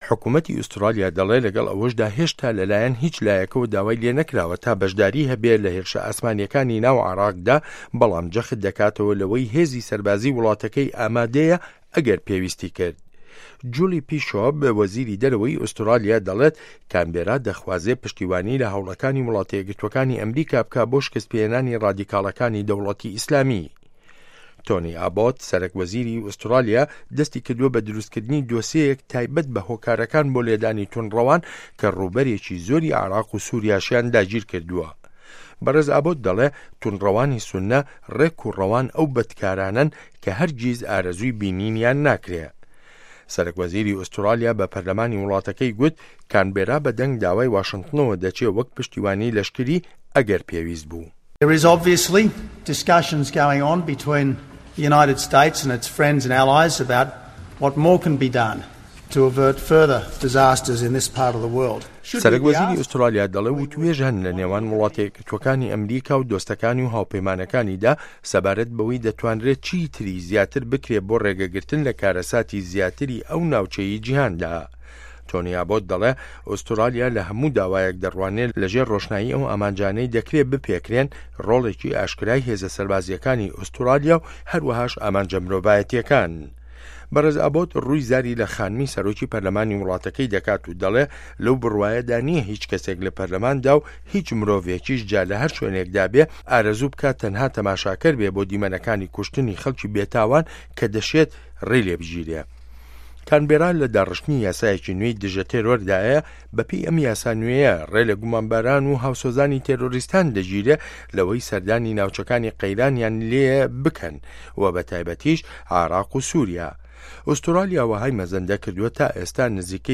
ڕاپـۆرتی ئوسترالیا و شه‌ڕی داعش